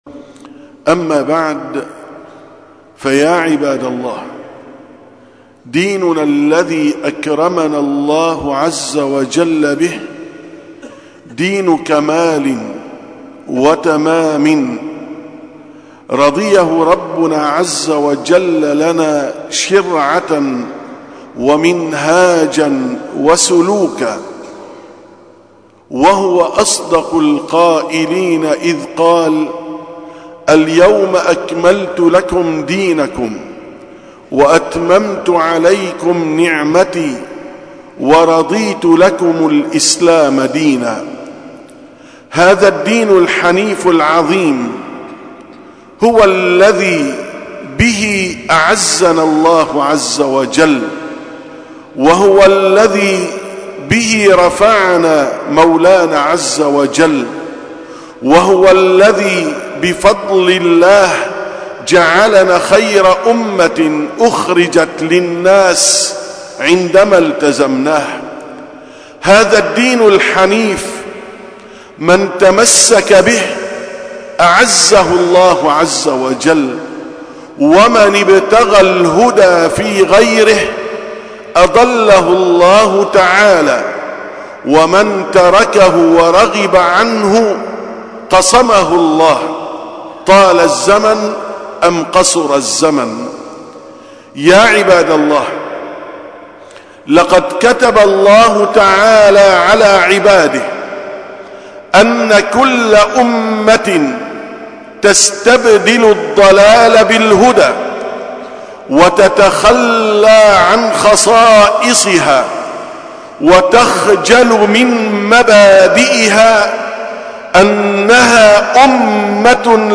687ـ خطبة الجمعة: واقعنا المرير